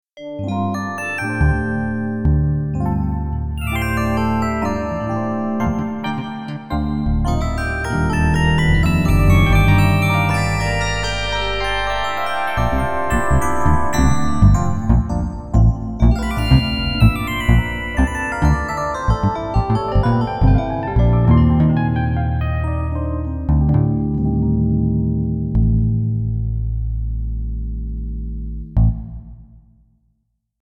I made some example music with this setup, starting with a multitrack recording with rosegarden of some hexter sounds with ingenuity processing, and then combined like above with a combined hexter/PD sound with ingenuity coupled LADSPA effects, which is then input to rosegarden, and recorded on a new track.
example 2  exa 1 with additonal PureData analog synth sound